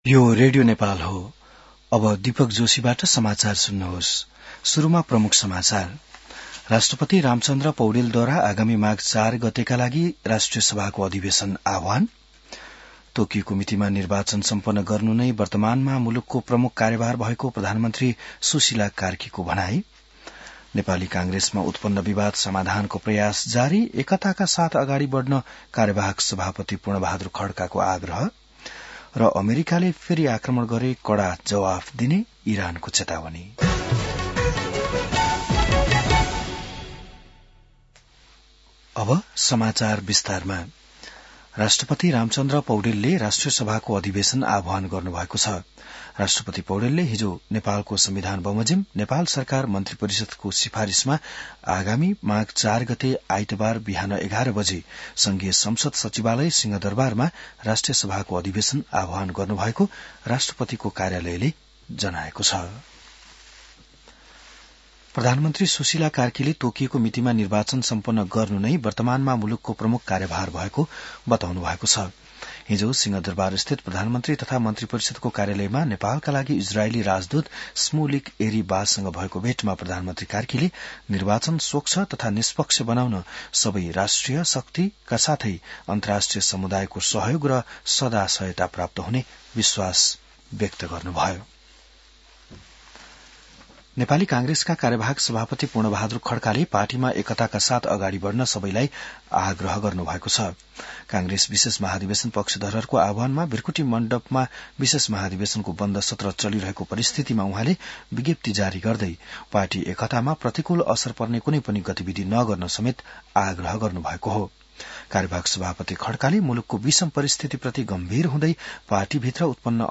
बिहान ९ बजेको नेपाली समाचार : २९ पुष , २०८२